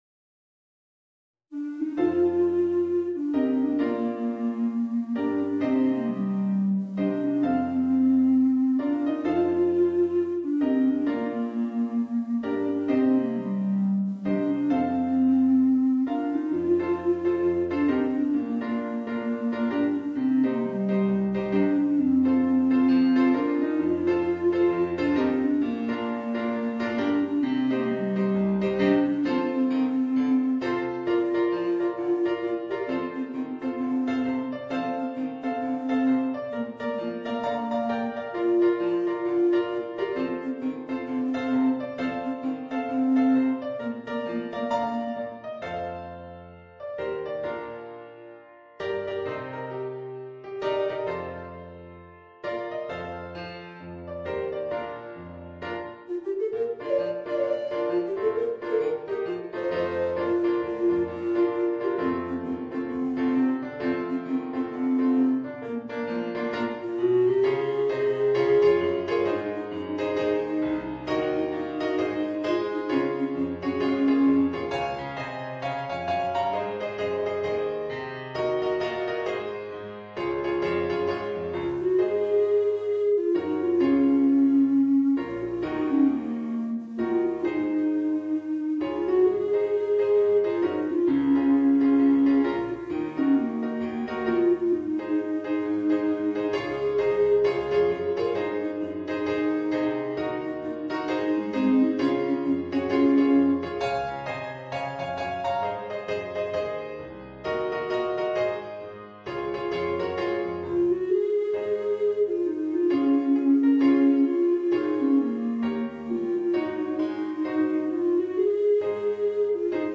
für Bassblockflöte und Klavier